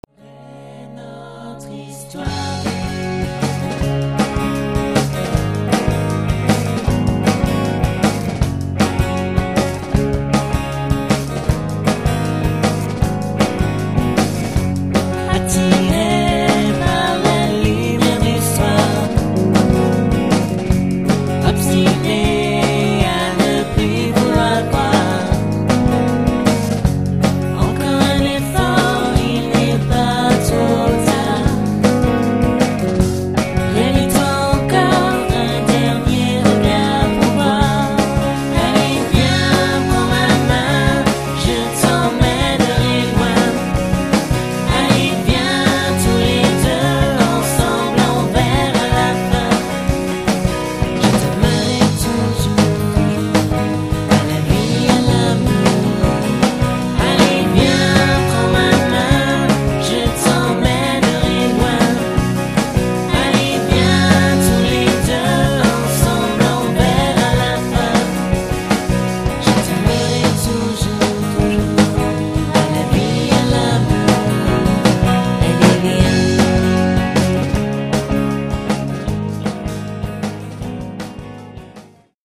Album autoproduit enregistré maison...